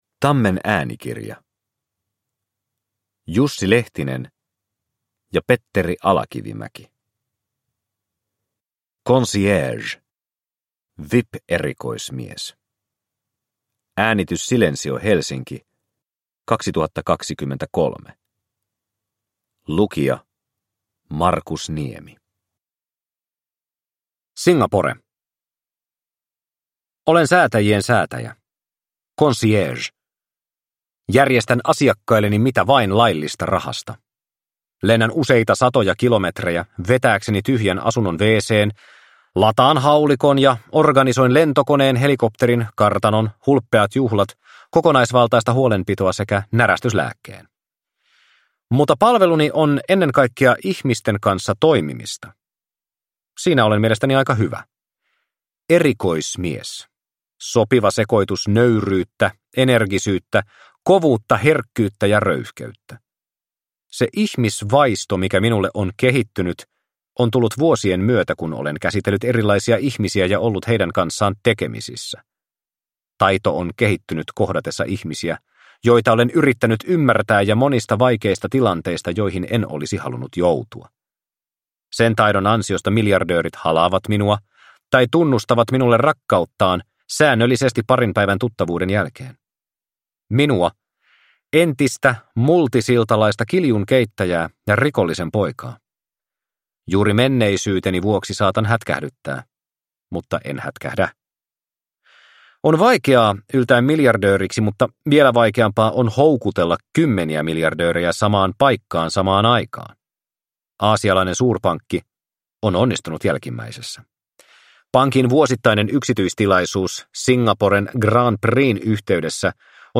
Concierge - VIP-erikoismies – Ljudbok – Laddas ner